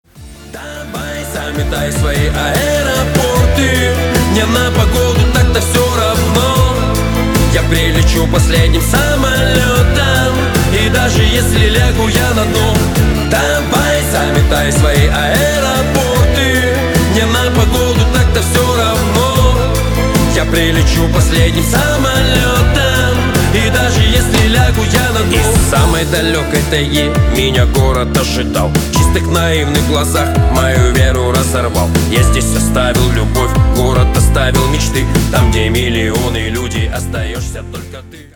• Качество: 320, Stereo
мужской вокал
лирика
душевные
русский рэп